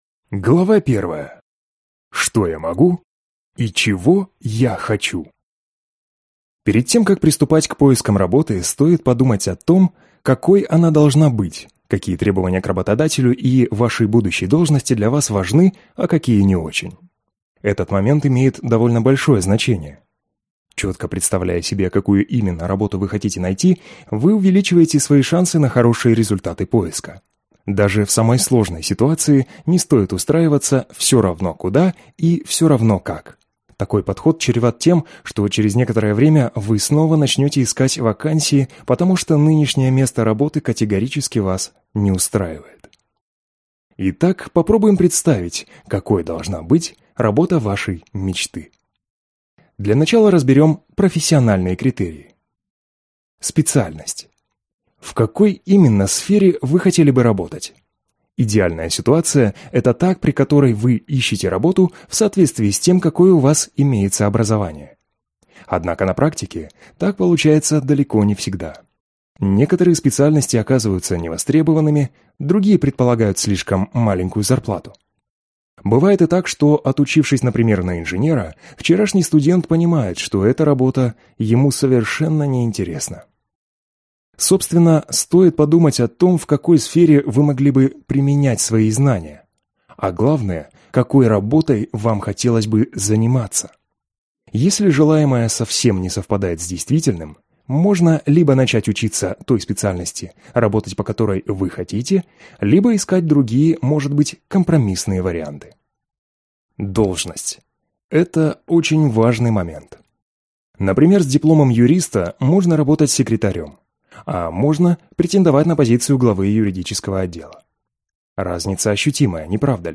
Аудиокнига Легкий способ найти работу | Библиотека аудиокниг